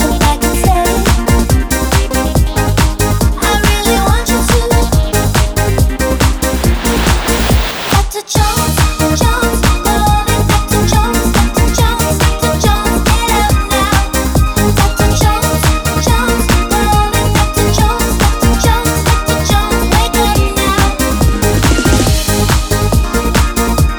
Duets